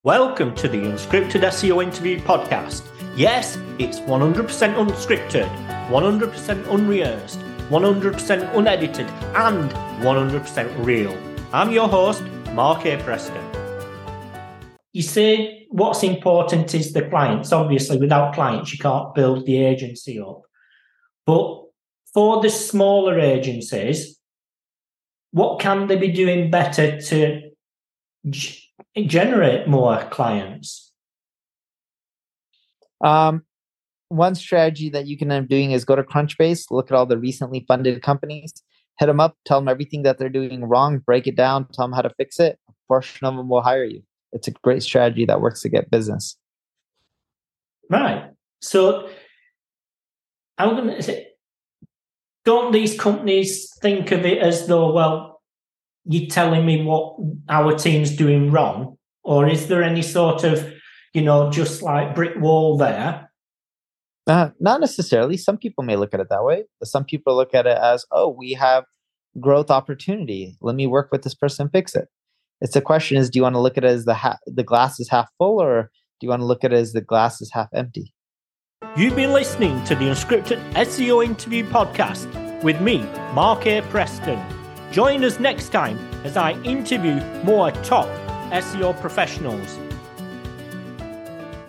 It is a conversation that promises not only strategic insights but also fosters a positive outlook towards business development.